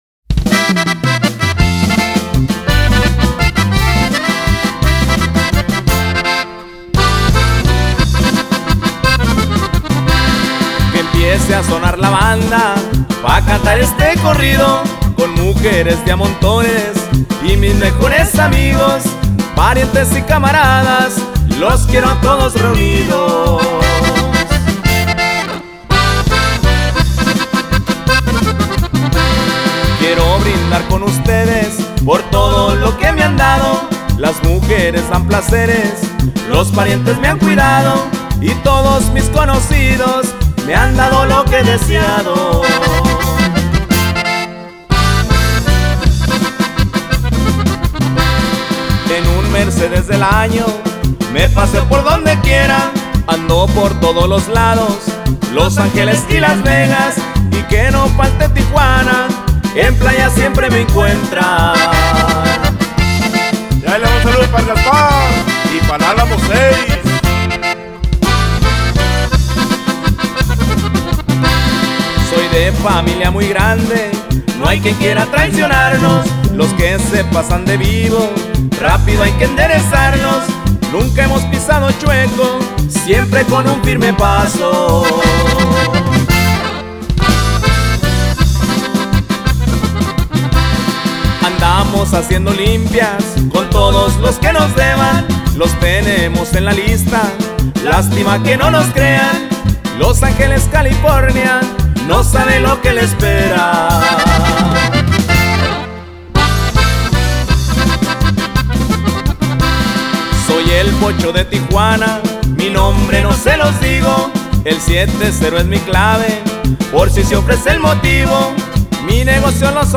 música Regional Mexicana